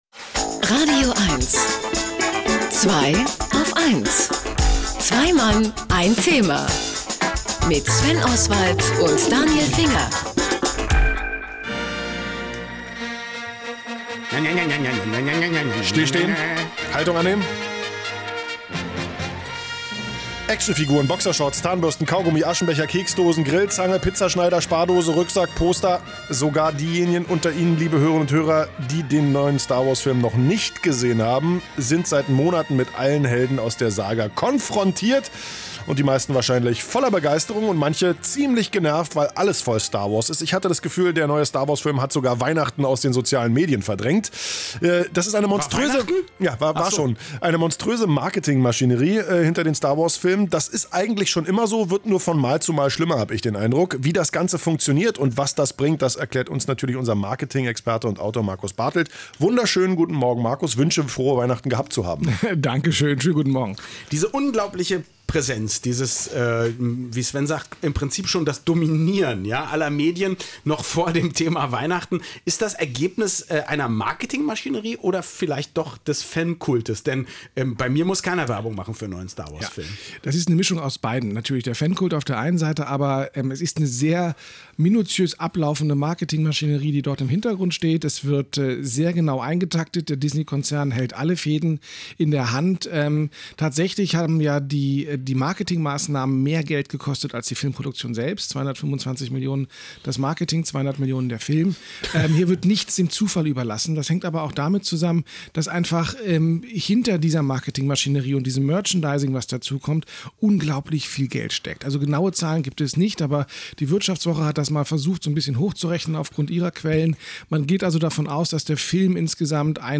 Endlich läuft der langersehnte neue Star Wars-Teil in den Kinos, begleitet von einem monströsen Marketing-Feuerwerk… schrieb ich „monströs“?? Zufälligerweise lautet an diesem Sonntag das Thema bei Zweiaufeins „Monster“ und ich war in das radioeins-Studio eingeladen, um ein wenig über diese Kampagne zu erzählen: